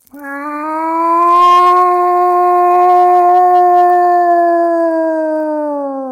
meows-8.mp3